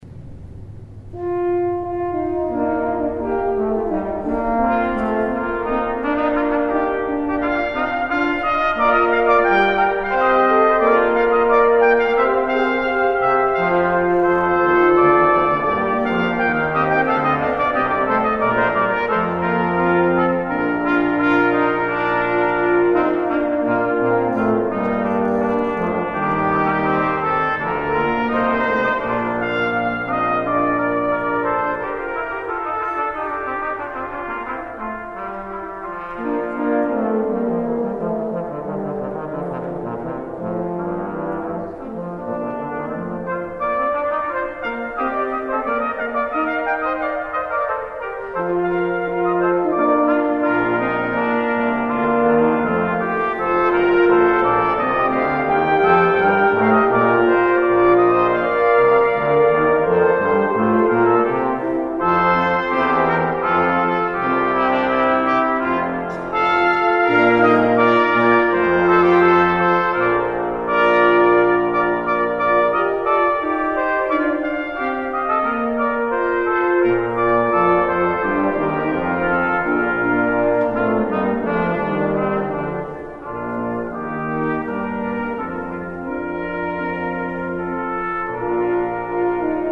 preludes as guests arrive (mp3) and take their seats, the procession, accompanying a singer during the ceremony, recessional, receiving line music, AND continue with
Our trumpet trio makes a great impression outdoors, as does the brass quintet.